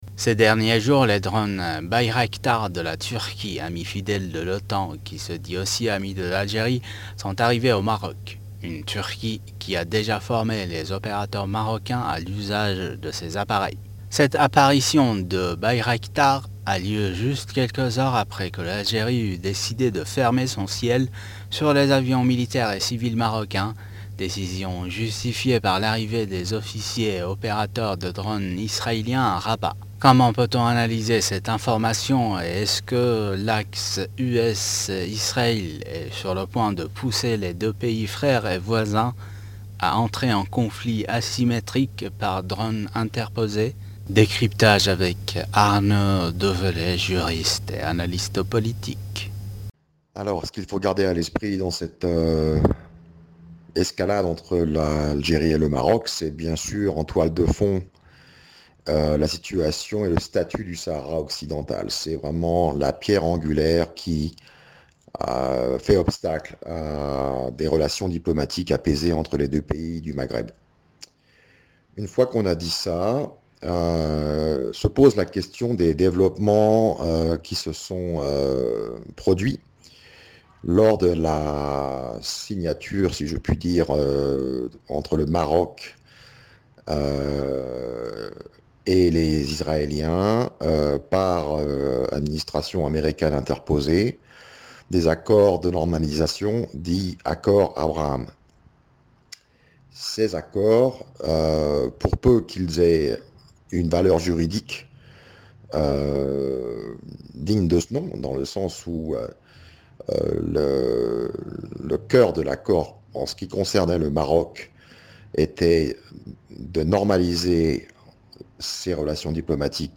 juriste international, s'exprime sur le sujet.